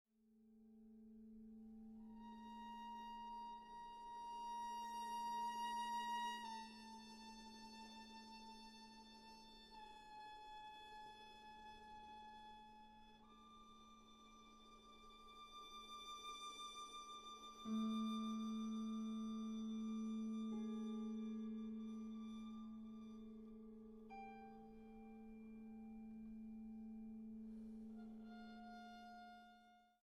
para violín y piano